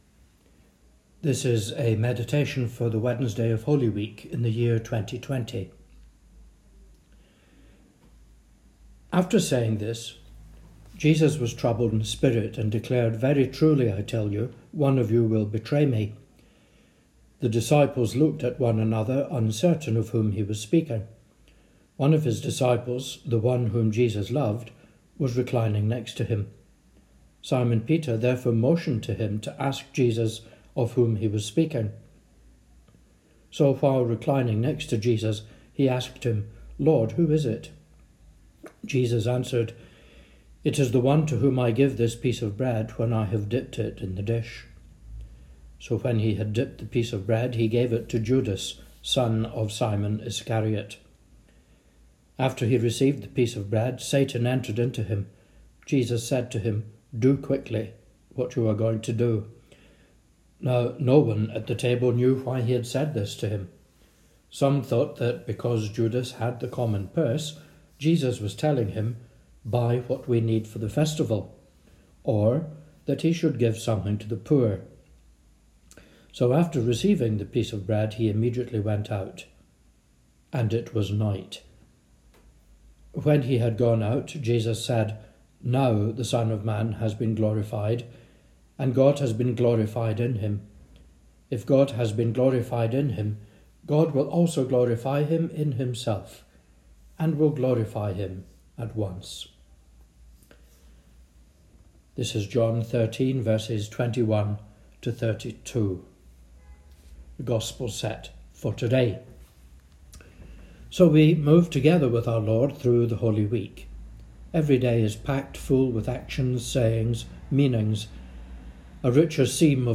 Sermons & Services
Audio Sermon Sunday 24 November 2019 Feast of Christ the King